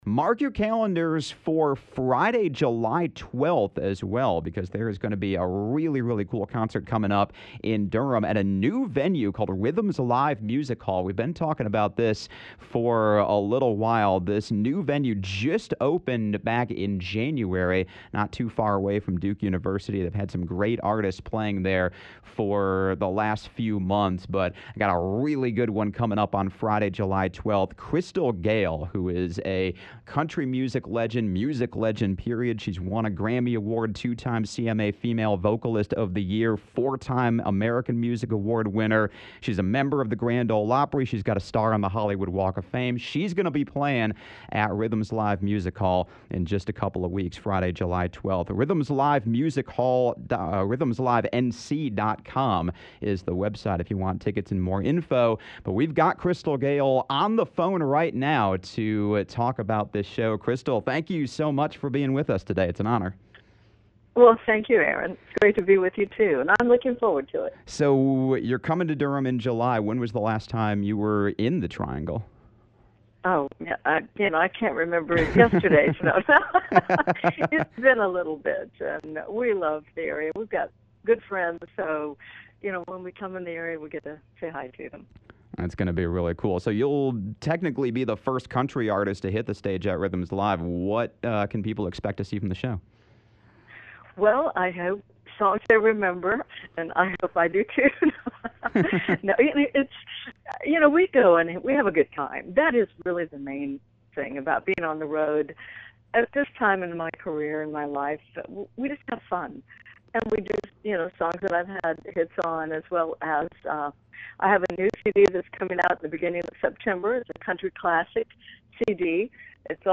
(Conversation sponsored by Rhythms Live Music Hall.)